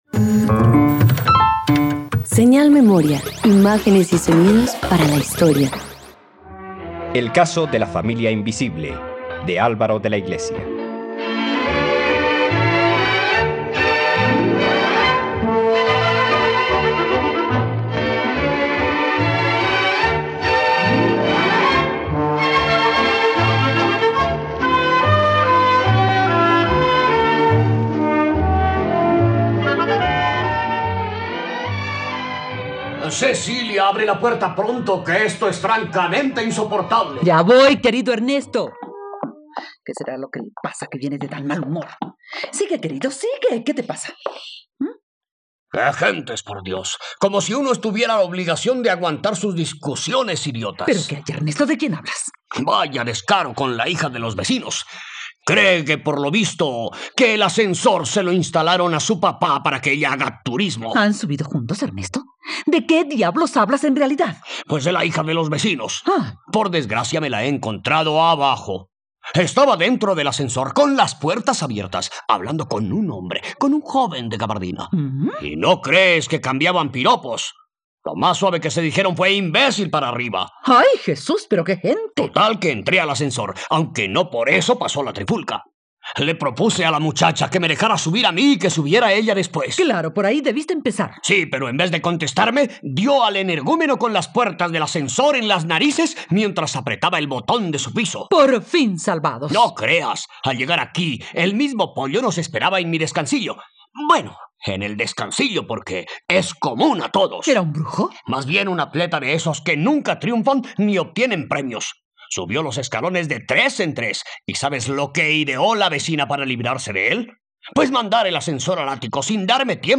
El caso de la familia invisible - Radioteatro dominical | RTVCPlay